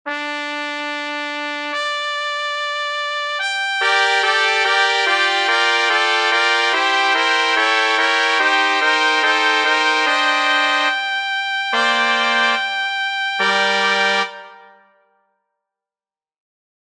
Key written in: G Minor
How many parts: 4
Type: Female Barbershop (incl. SAI, HI, etc)
The post should be sung operatically.
All Parts mix: